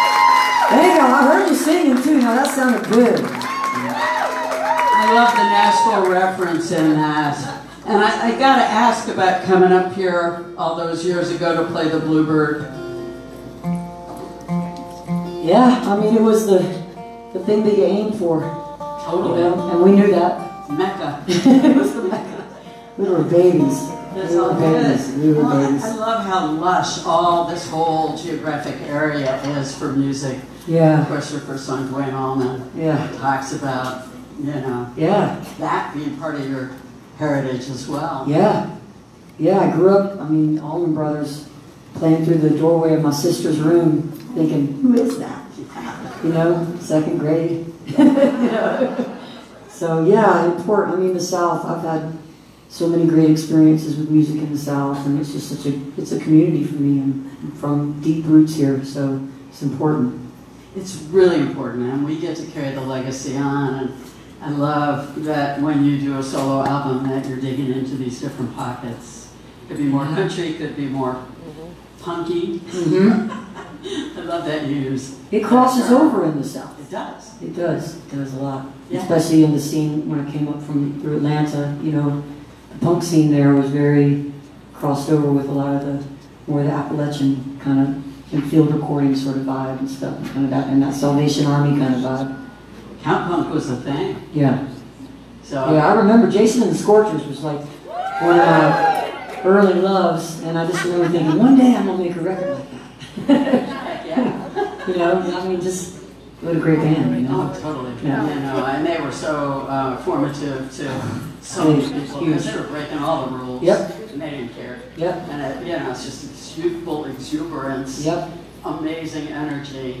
(captured from facebook live stream)
04. talking with the crowd (5:33)